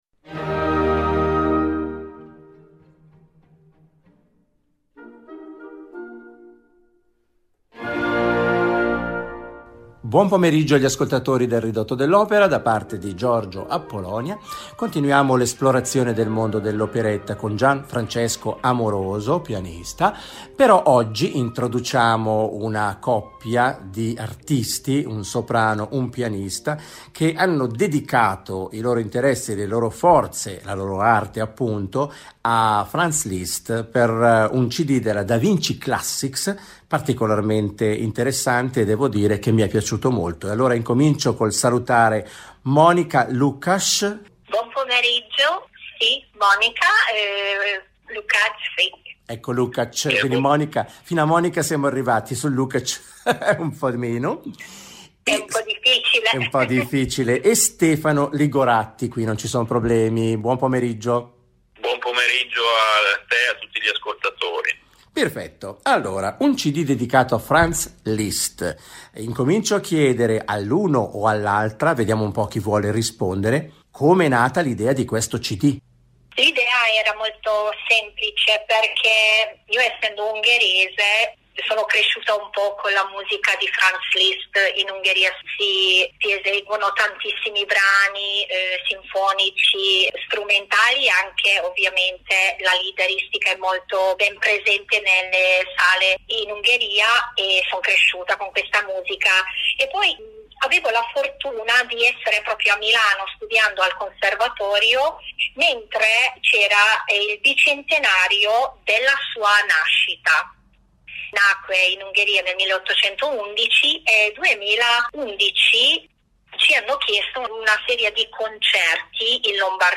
protagonista della proposta d'ascolti il soprano Anneliese Rotenberger.